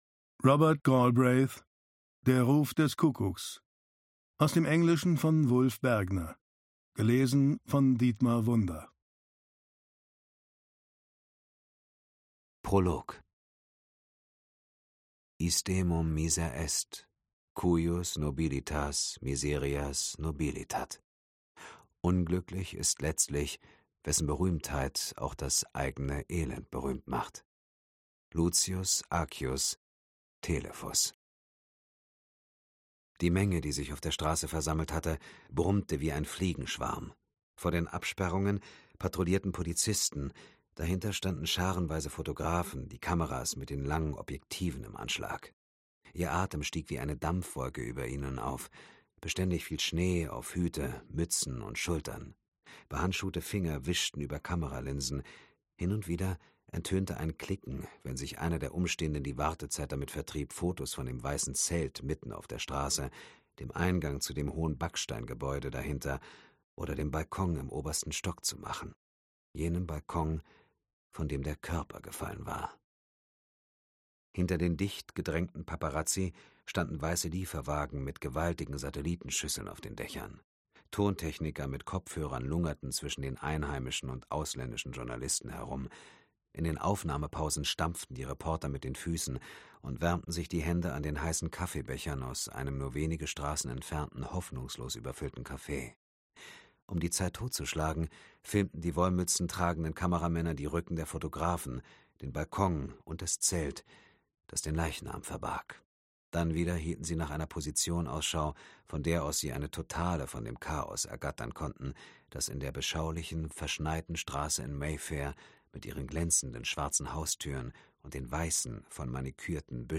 Dietmar Wunder (Sprecher)
Ungekürzte Lesung